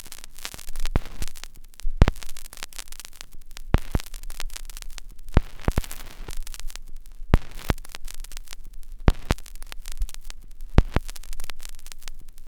DAMAGE    -R.wav